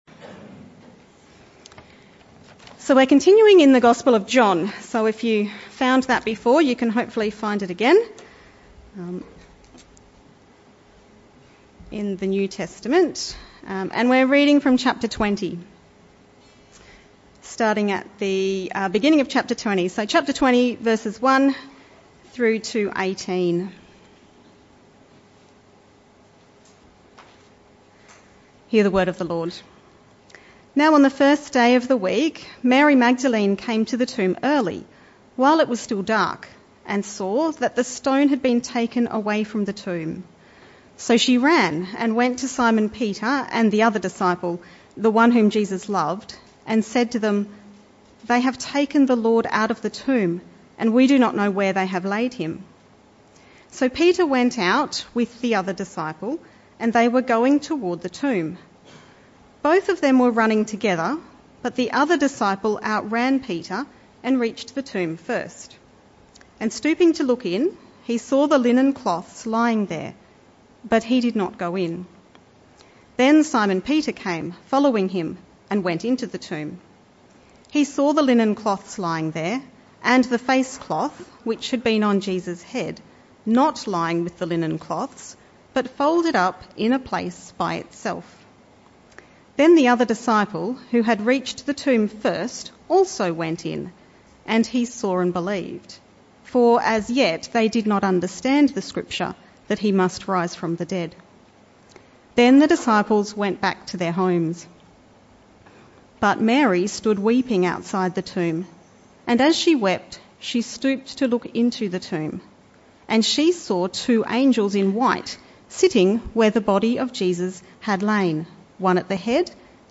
This talk was a one-off talk on Easter Sunday.